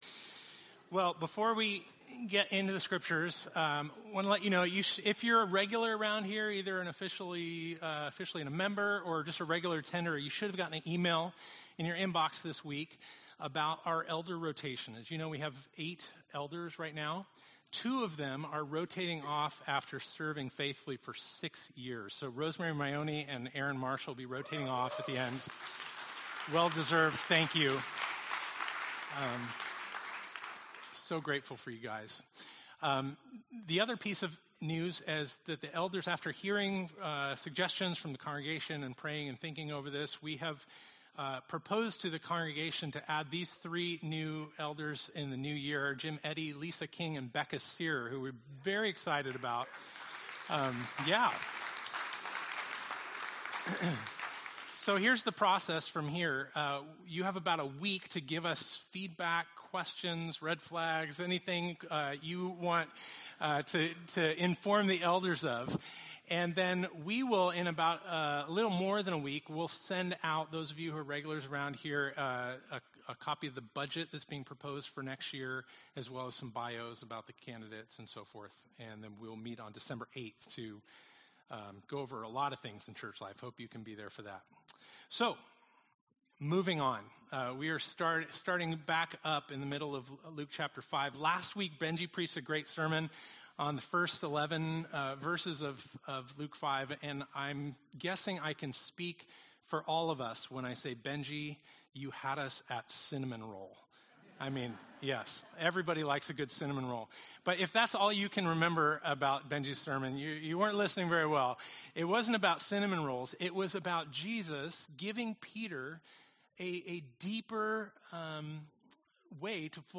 Gospel of Luke Passage: Luke 5:12-32 Service Type: Sunday